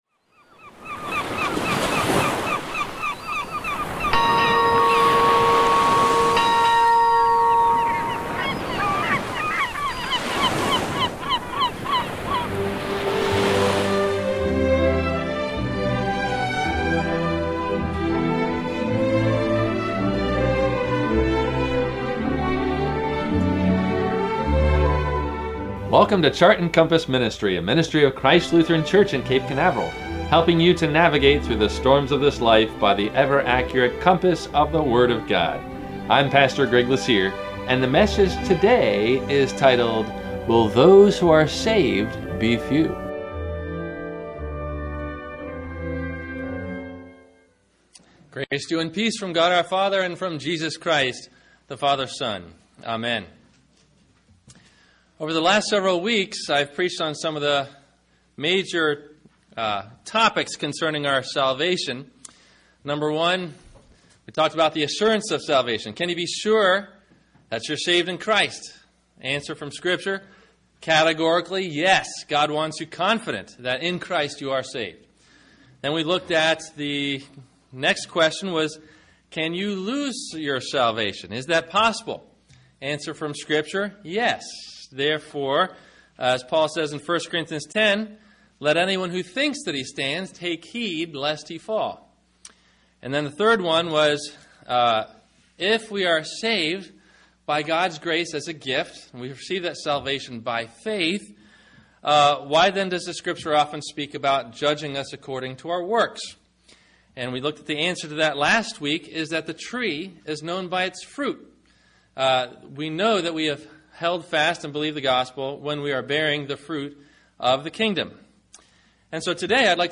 Judged According To Works? – WMIE Radio Sermon – October 13 2014